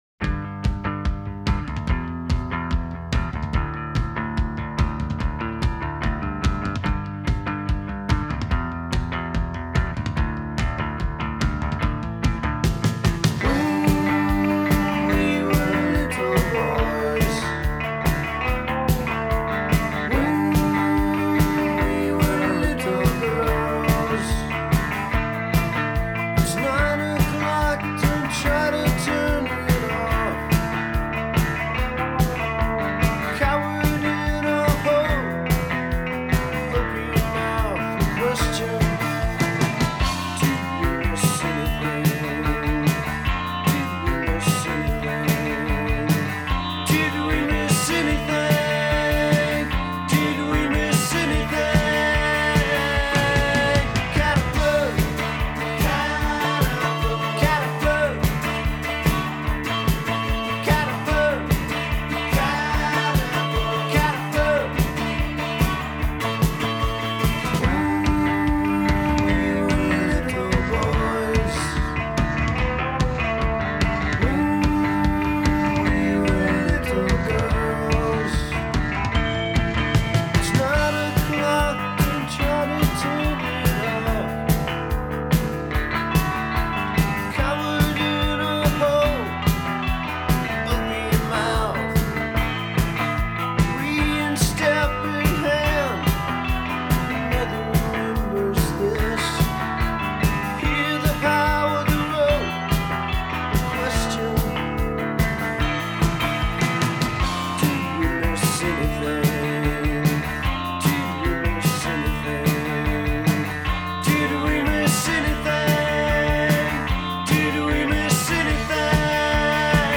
The instruments are easily identifiable.